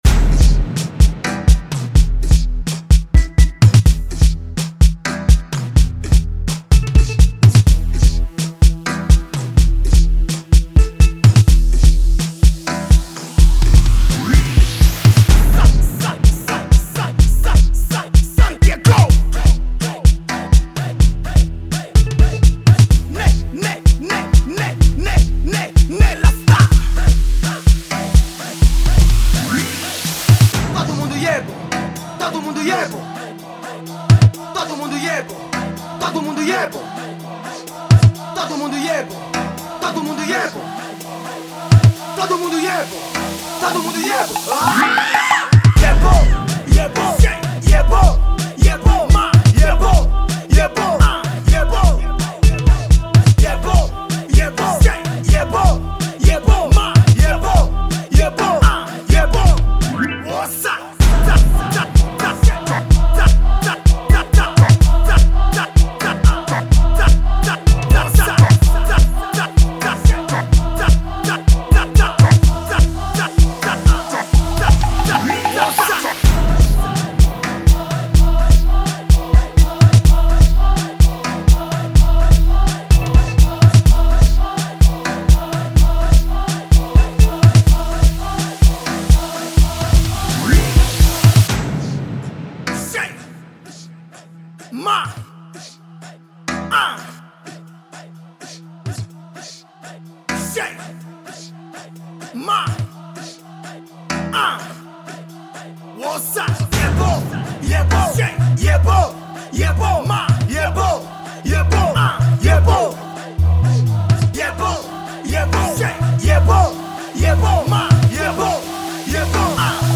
Afro House